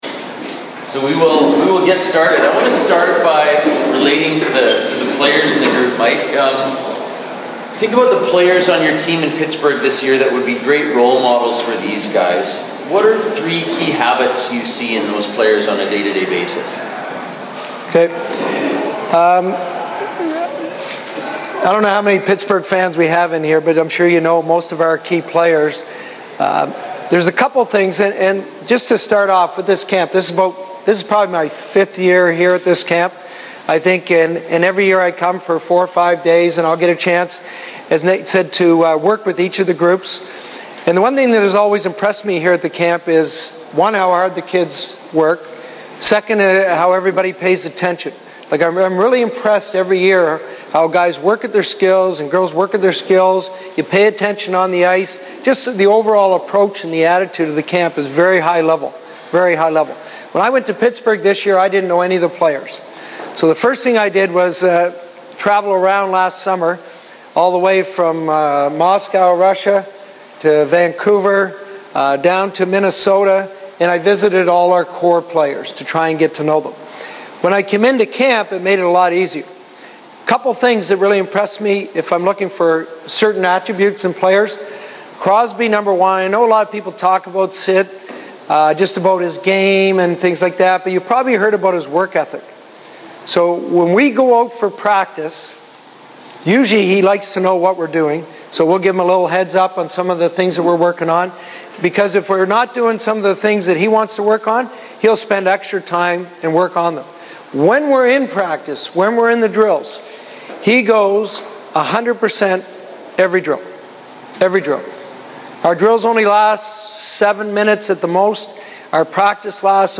Questions I asked Mike Johnston, Head Coach Pittsburgh Penguins:
[info_box]To hear Coach Johnston speak directly click the audio file below.